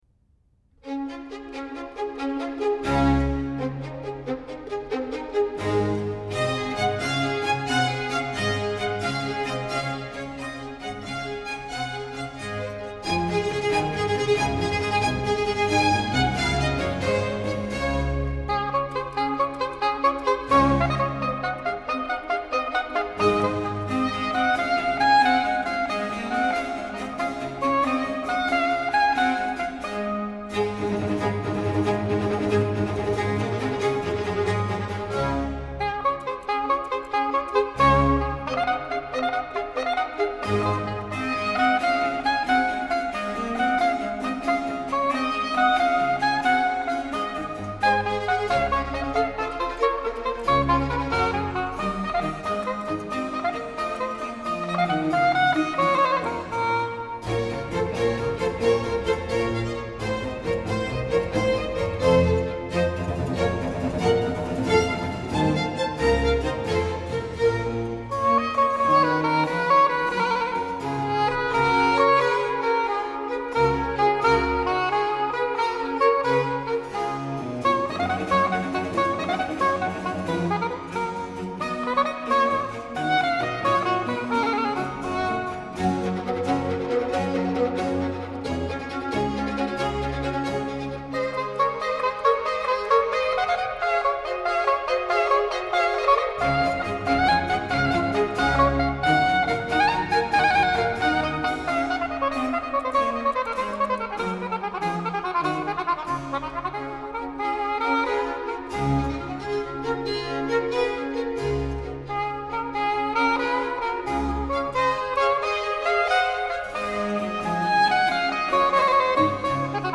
大调作品于巴洛克时期的运用通常为展现音乐欢愉的一面